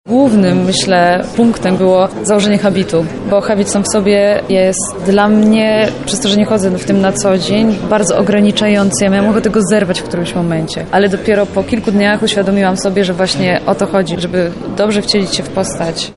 O swoich przygotowaniach do roli mówi sama aktorka.